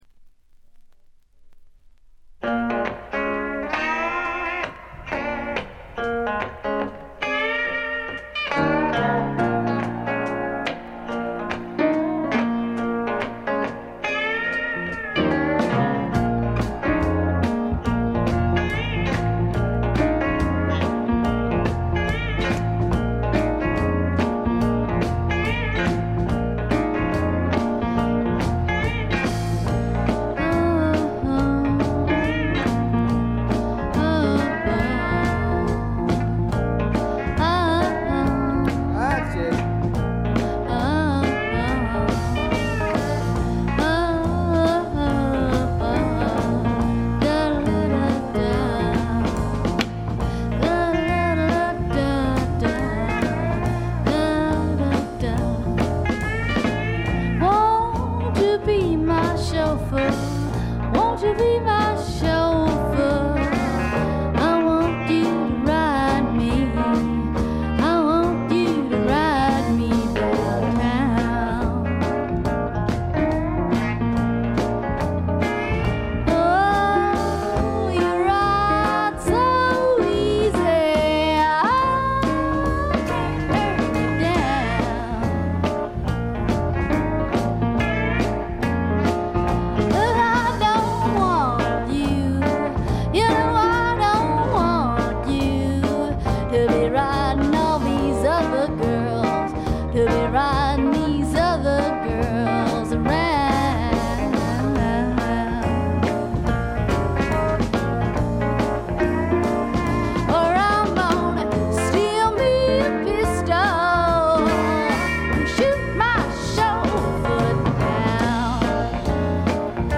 軽微なチリプチ少し。
わざわざ言うまでもなく米国東海岸を代表するグッドタイムミュージックの超絶名盤です。
試聴曲は現品からの取り込み音源です。